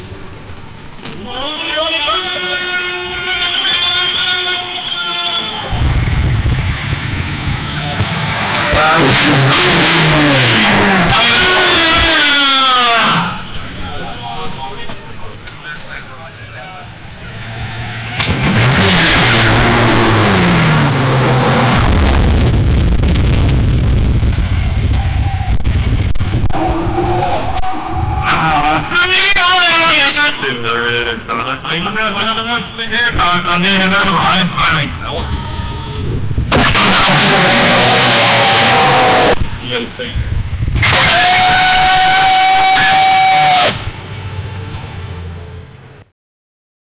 a-person-announcing-of-th-qllko433.wav